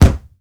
punch_low_deep_impact_09.wav